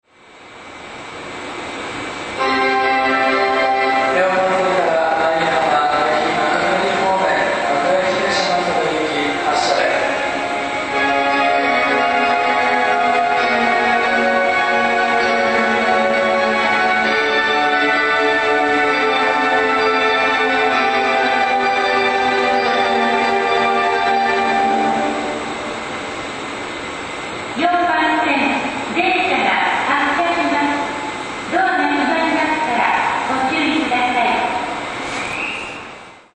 Verde Rayo 京葉線オリジナルバージョンで全ホーム共通です